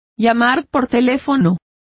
Complete with pronunciation of the translation of phoning.